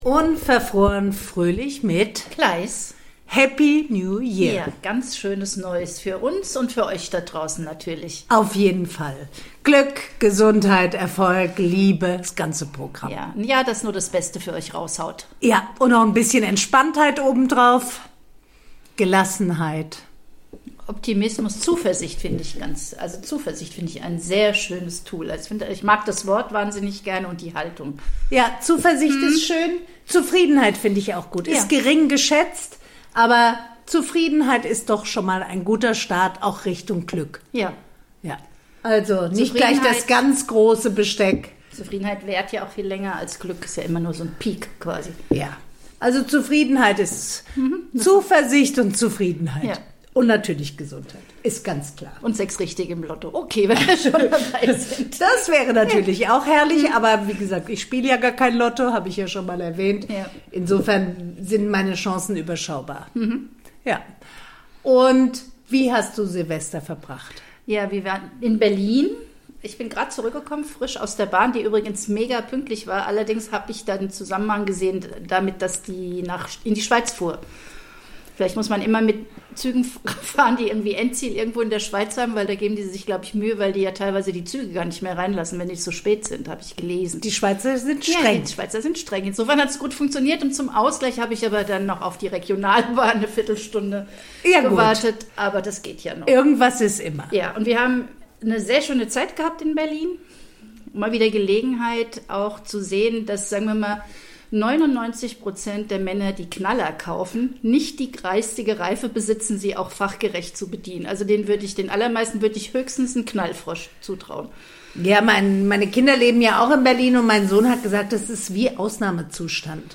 reden die beiden Podcasterinnen über Weihnachtspöbeleien, über Silvestergeböller und über den Wunschzettel an 2026.